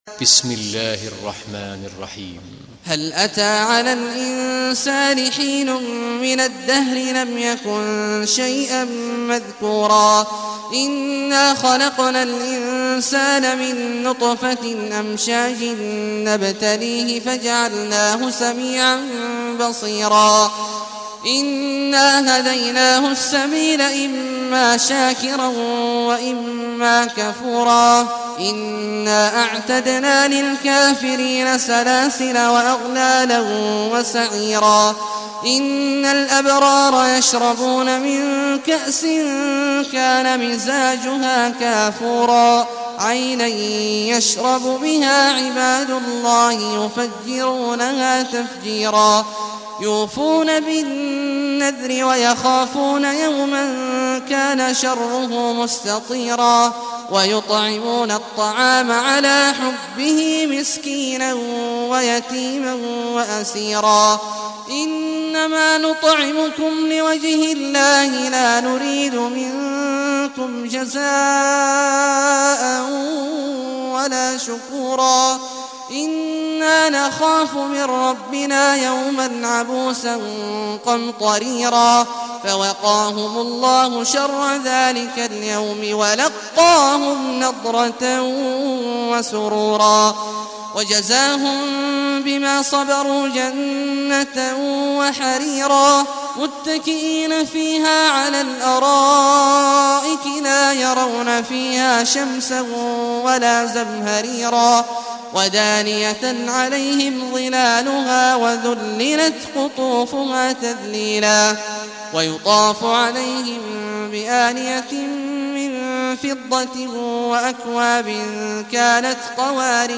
Surah Al-Insan Recitation by Sheikh Awad al Juhany
Surah Al-Insan, listen or play online mp3 tilawat / recitation in arabic in the beautiful voice of Sheikh Abdullah Awad al Juhany.